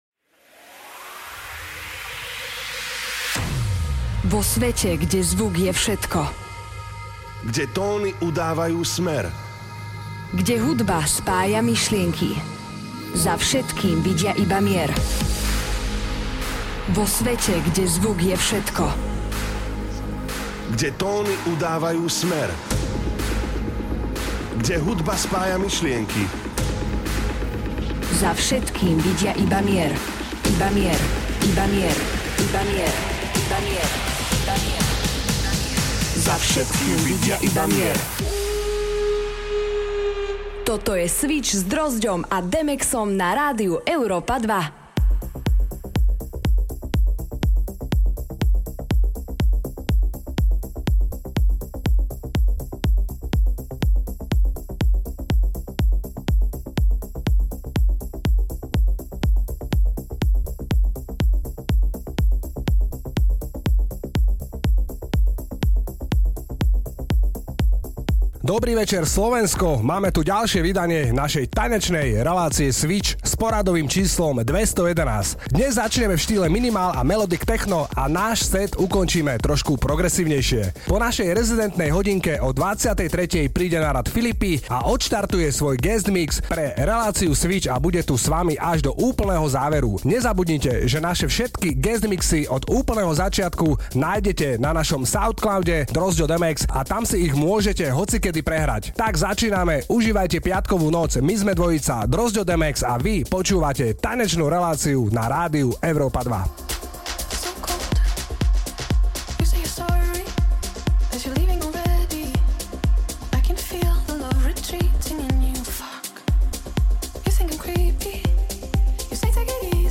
Preži každý piatok v eufórii s novou tanečnou hudbou!
rádio show
zameraná na elektronickú tanečnú hudbu EDM